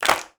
DrClap17.wav